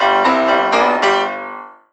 pianoriff.wav